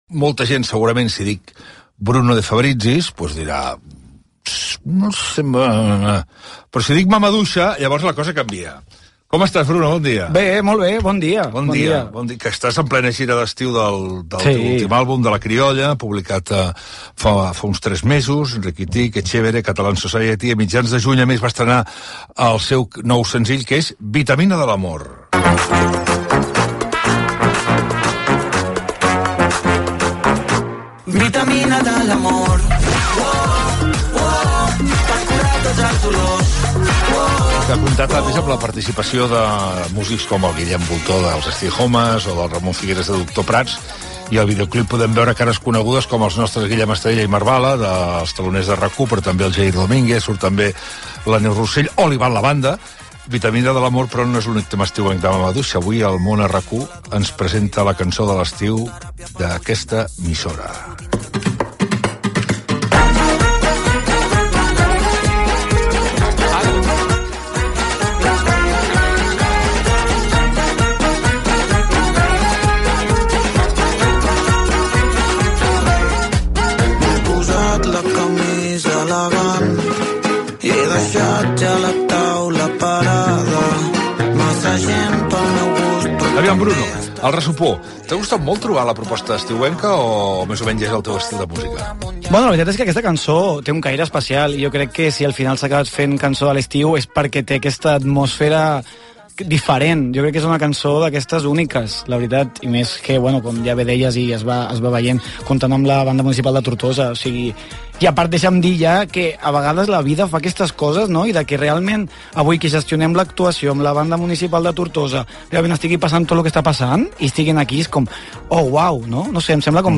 Entrevista
Interpretació del tema musical amb membres de la Banda Municipal de Música de Tortosa. Gènere radiofònic Info-entreteniment Presentador/a Basté